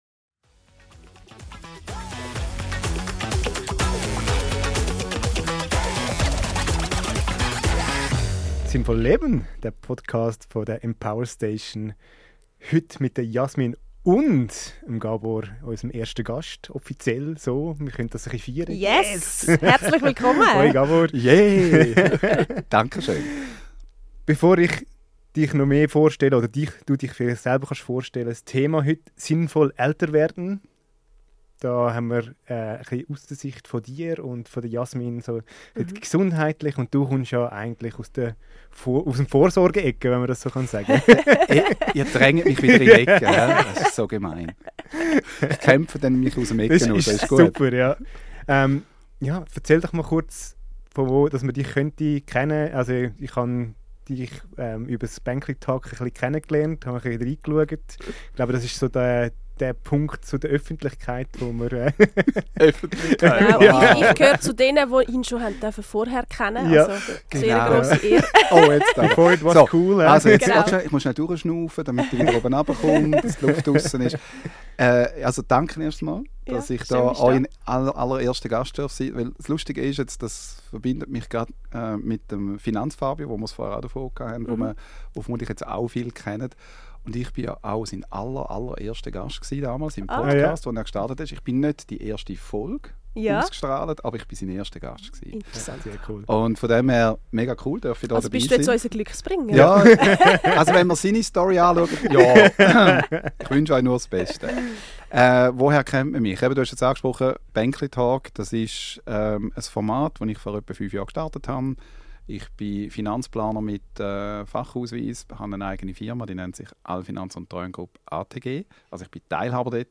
Gemeinsam sprechen sie über die Herausforderungen und Chancen des Älterwerdens – und darüber, wie wir finanzielle Sicherheit, emotionale Reife und sinnvolle Zukunftsgestaltung in Einklang bringen können. Ein Gespräch für alle, die nicht nur älter, sondern bewusster leben möchten – mit Klarheit, Würde und dem Mut zum Wandel.